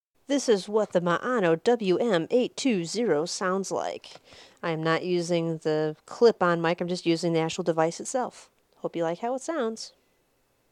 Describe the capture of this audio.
I was able to use this cable to connect the transmitter to my computer and record myself using audacity.